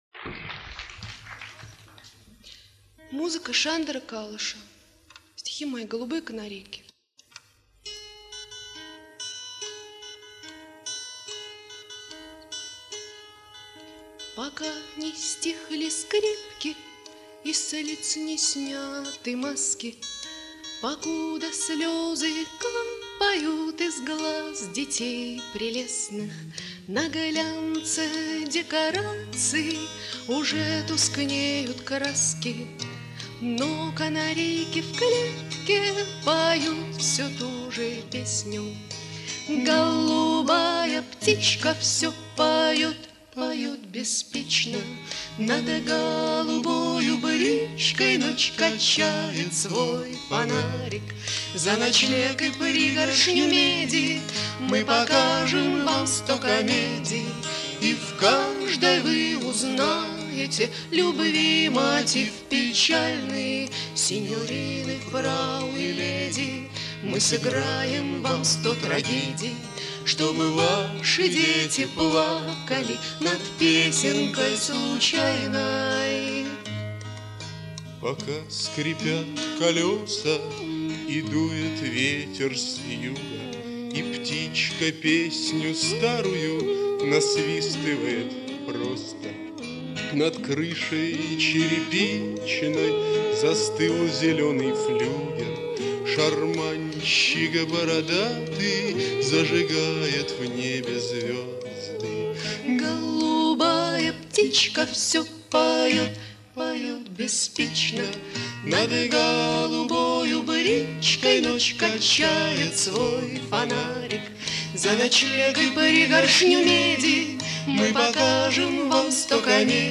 Канарейки